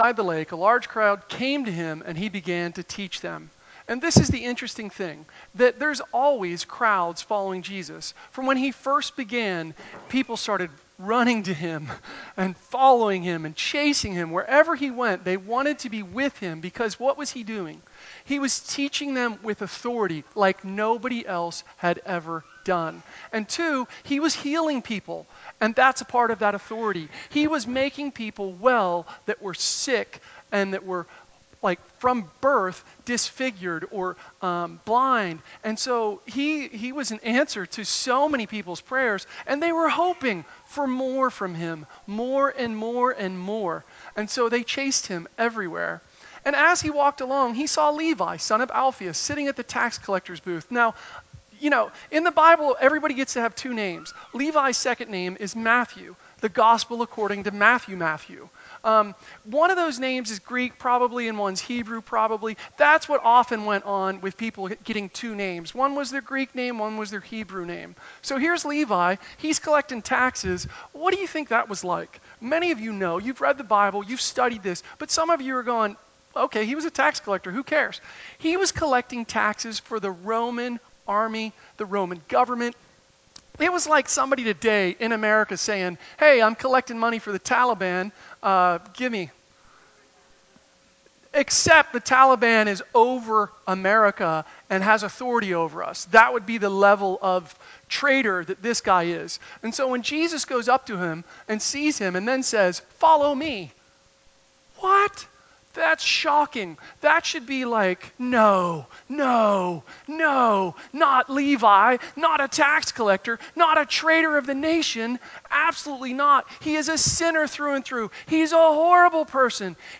Sermons in MP3 format from 2019